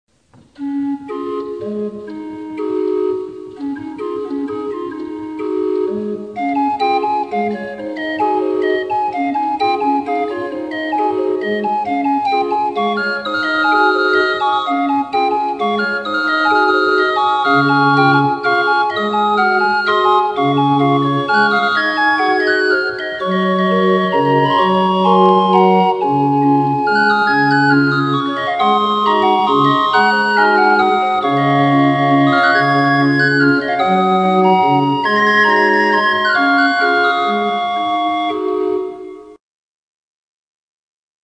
DREHORGELSPIELER
Die Drehorgeln sind keine elektronischen Imitate, sondern gepflegte Originalinstrumente aus der Zeit um 1900.
Alle Hörproben sind original Aufnahmen mit den Drehorgeln !
drehorgel_leise rieselt der schnee.mp3